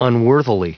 Prononciation du mot unworthily en anglais (fichier audio)
Prononciation du mot : unworthily